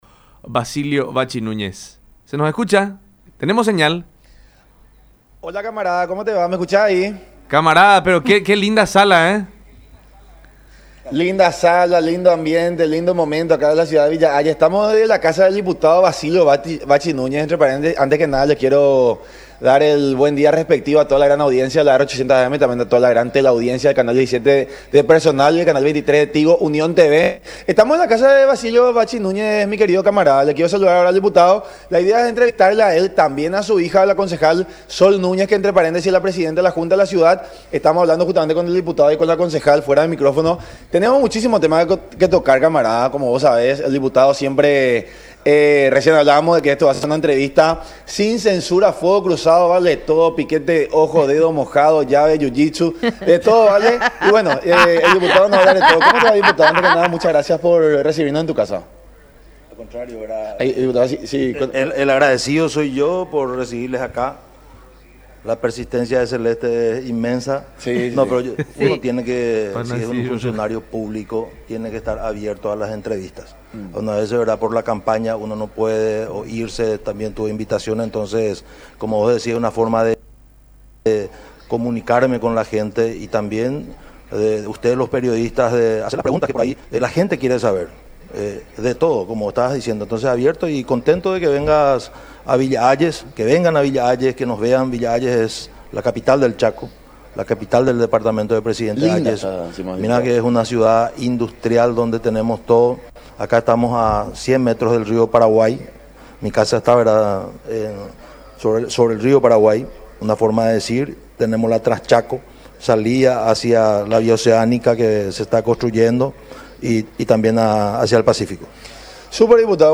Él fue electo presidente de la República y tiene que dedicarse a gobernar”, durante el programa La Unión Hace La Fuerza por Unión TV y radio La Unión, afirmando que la unidad de la ANR se está produciendo “de abajo hacia arriba”, es decir, desde las bases dirigenciales hacia los líderes de movimientos.